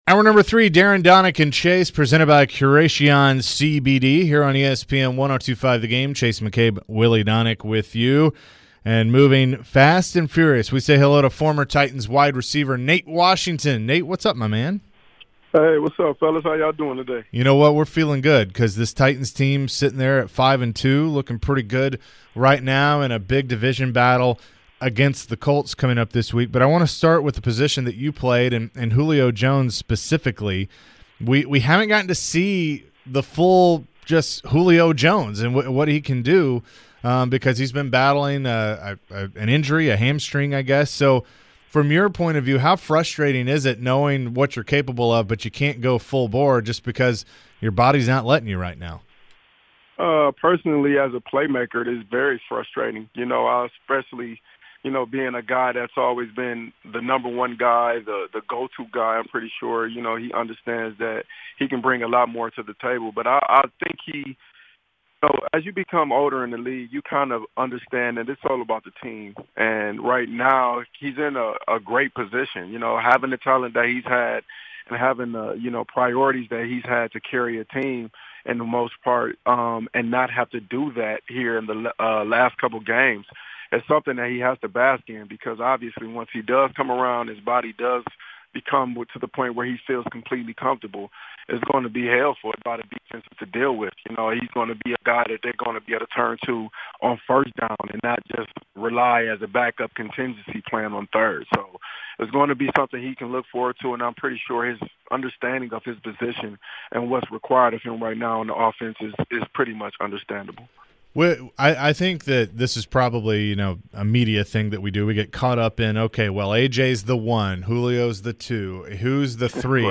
Former Titans WR Nate Washington joined the DDC to give his thoughts on the evolution of the Titans offense, the two big wins over the Bills and Chiefs and more!